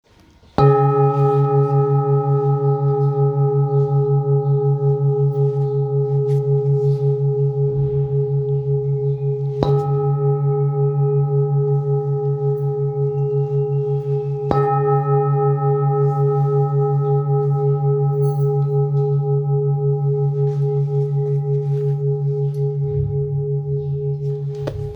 Handmade Singing Bowls-31777
Singing Bowl, Buddhist Hand Beaten, with Fine Etching Carving, Shakyamuni Buddha, Thangka Color Painted
Material Seven Bronze Metal